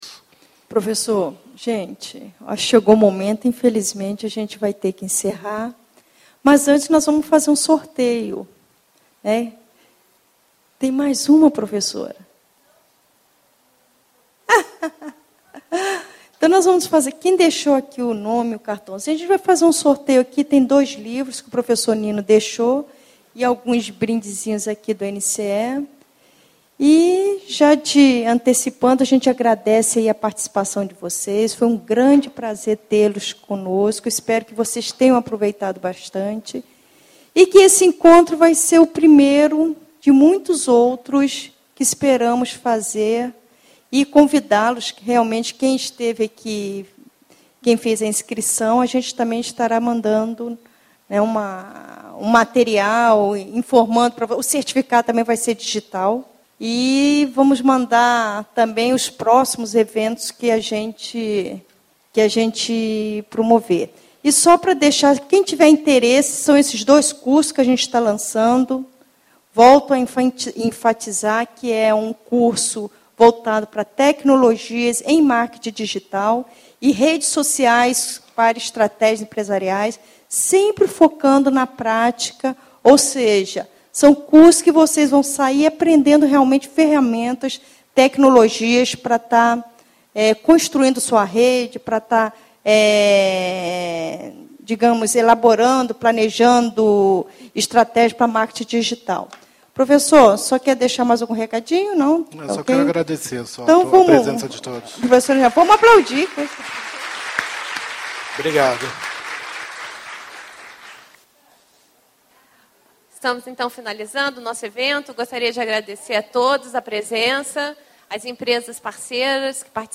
I F�rum de tecnologias em Marketing Digital da UFRJ - 17/04/2012
Encerramento